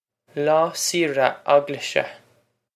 Pronunciation for how to say
law see-ra og-lish-a
This is an approximate phonetic pronunciation of the phrase.